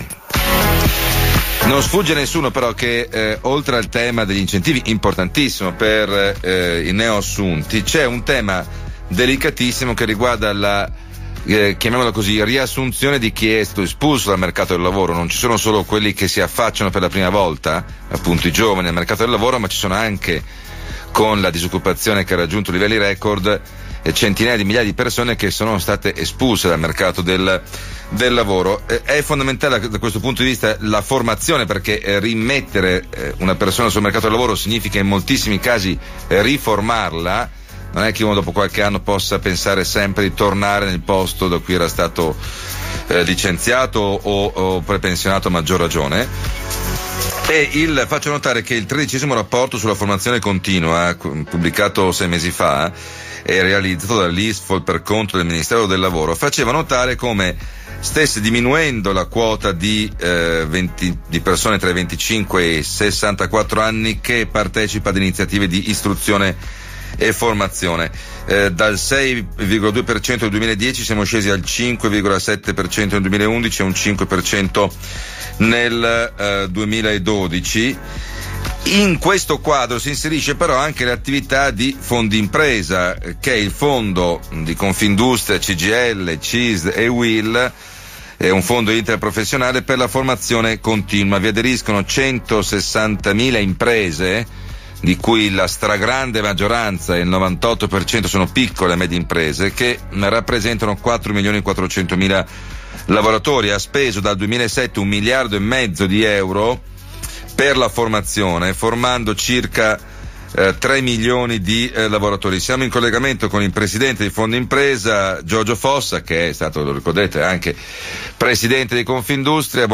• Sebastiano Barisoni intervista Giorgio Fossa
IntervistaFossa.mp3